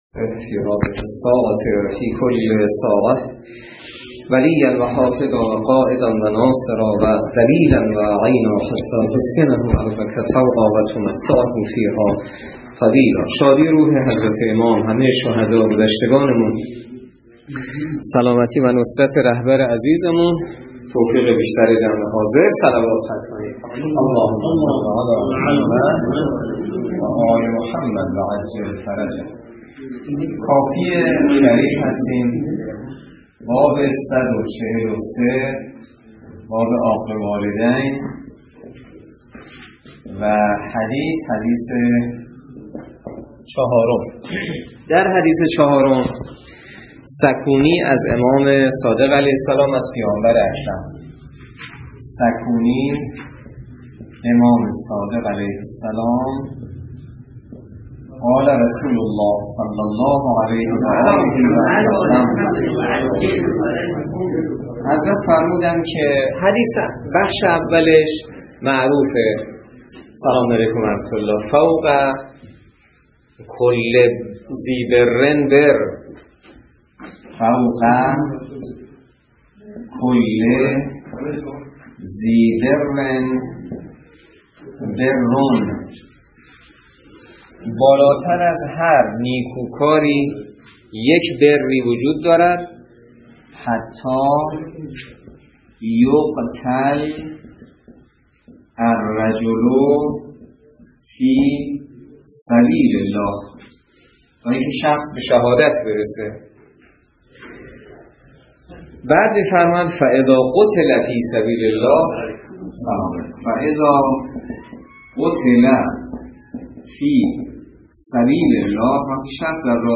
درس فقه الاجاره نماینده مقام معظم رهبری در منطقه و امام جمعه کاشان - سال سوم جلسه شصت و یک